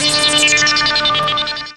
Synth-D.wav